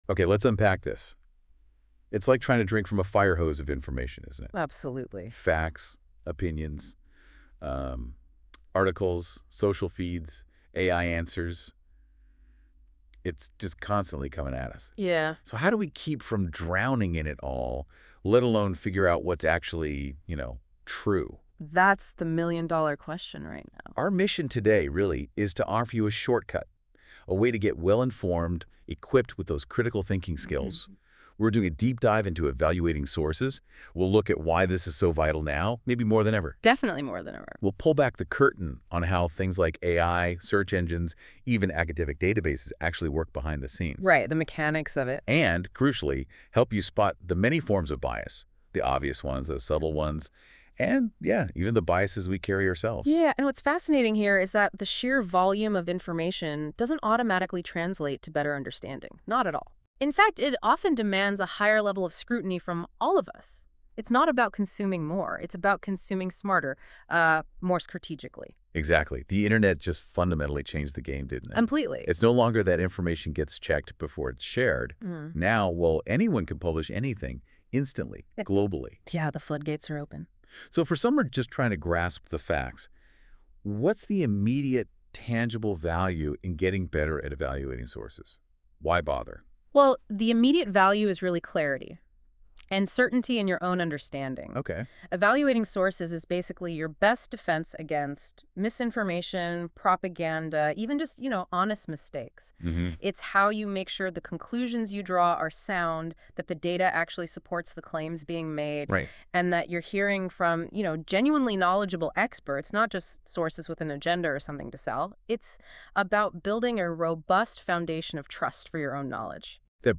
Activity: Listen to This Podcast That was created using AI from these materials.